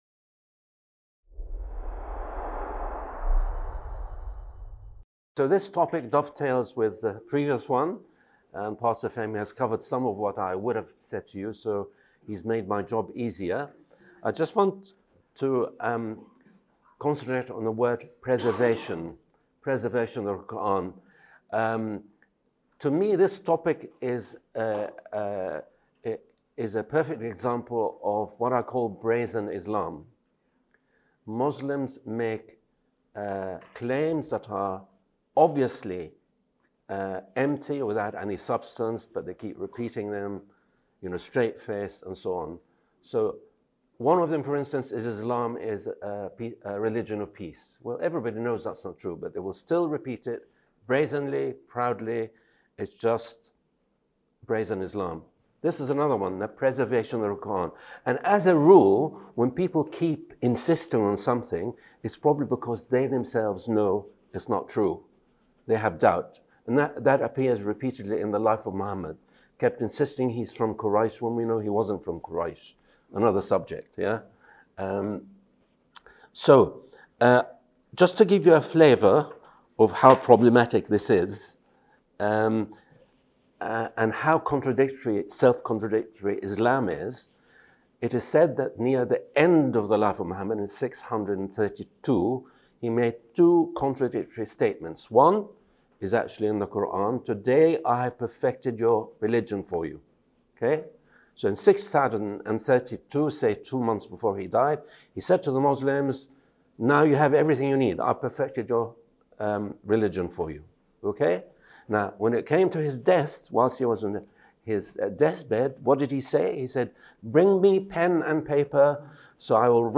This talk answers these questions and more as we evaluate the authenticity of Islam’s holy book and how it should shape our
Event: ELF Muslim Ministry Network